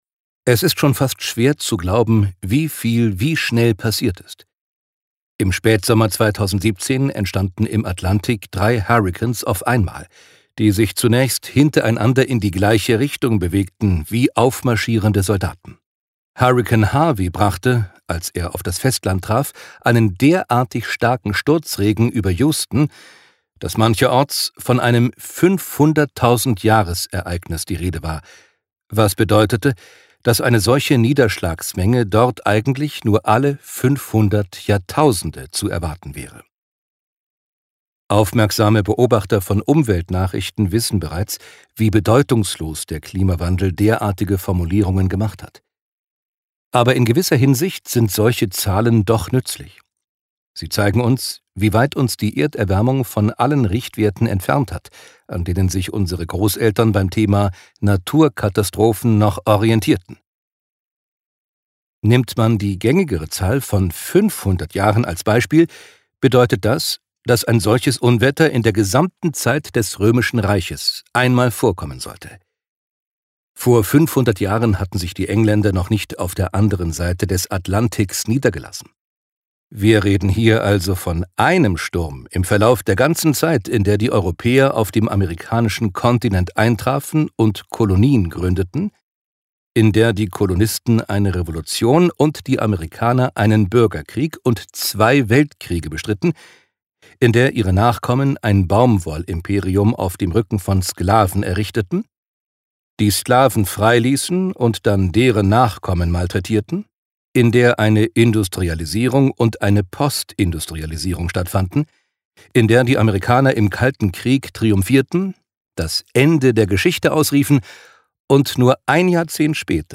Genre: Lesung.